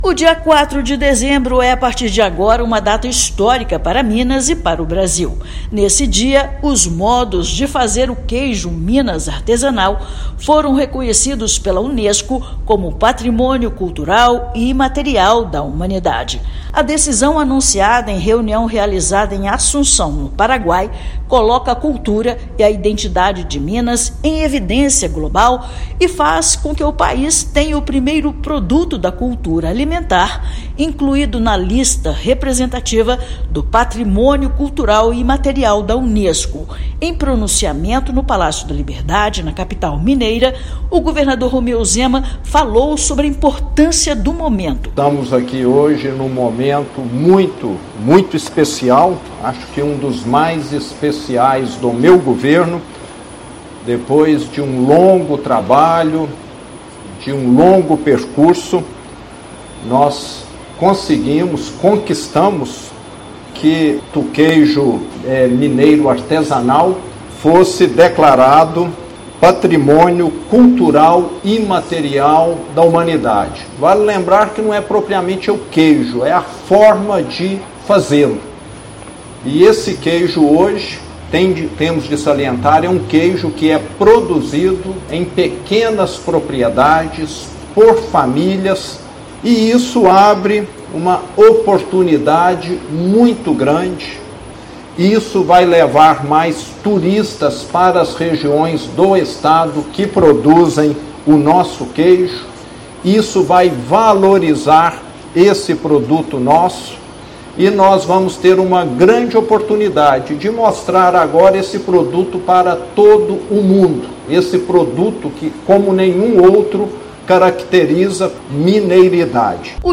[RÁDIO] Governo do Estado celebra reconhecimento histórico dos Modos de Fazer o Queijo Minas Artesanal como Patrimônio Cultural Imaterial da Humanidade
Decisão foi anunciada pela Unesco nesta quarta-feira (4/12) em reunião em Assunção, no Paraguai. . Ouça matéria de rádio.